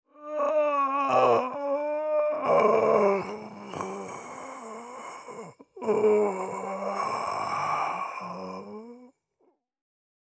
В подборке – резкие вскрики, предсмертные стоны и другие жуткие эффекты длиной от 1 до 16 секунд.
Мужчина перед смертью издает странный звук